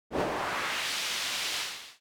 SE（風 長め）
さーっ。サーッ。風。